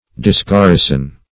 Meaning of disgarrison. disgarrison synonyms, pronunciation, spelling and more from Free Dictionary.
Disgarrison \Dis*gar"ri*son\, v. t. To deprive of a garrison.